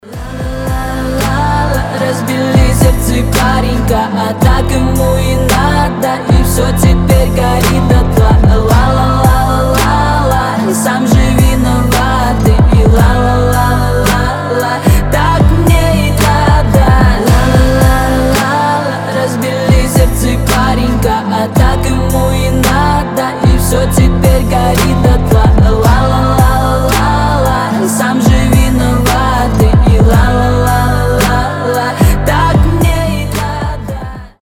гитара
мужской голос
лирика
грустные
спокойные
медленные